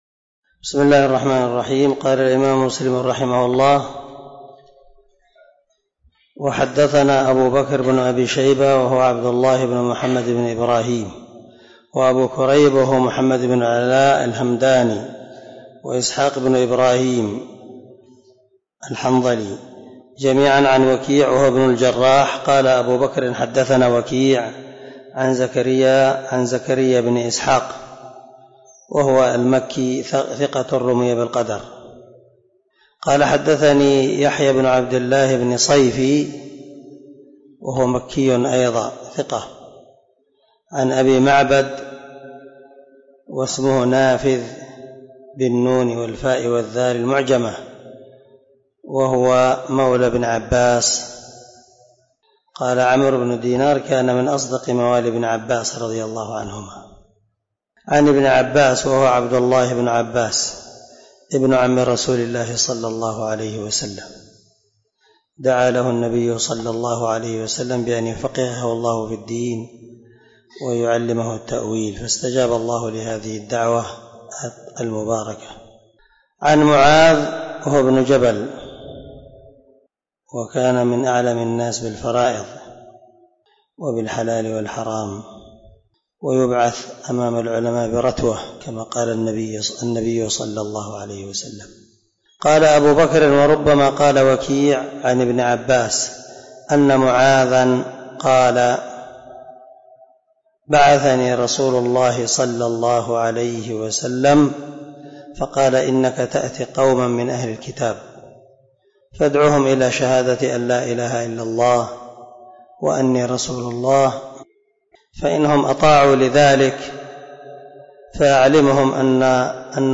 011الدرس 10 من شرح كتاب الإيمان حديث رقم ( 19 ) من صحيح مسلم